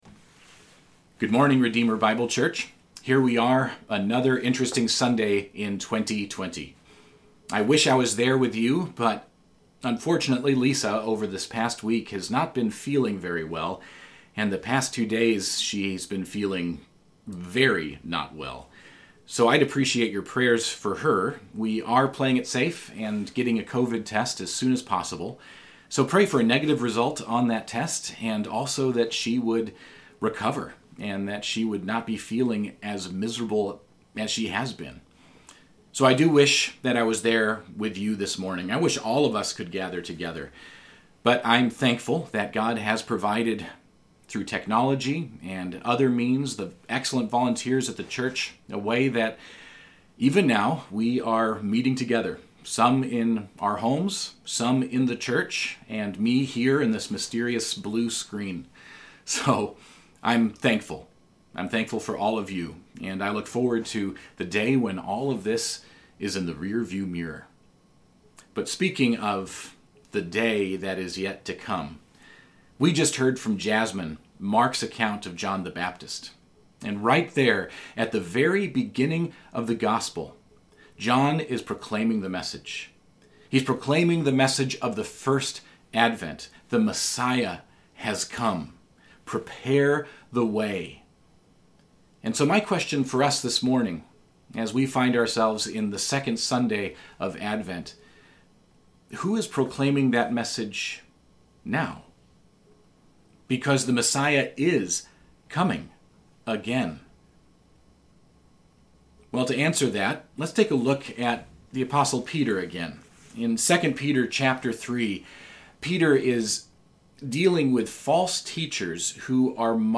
December 6, 2020 Worship Service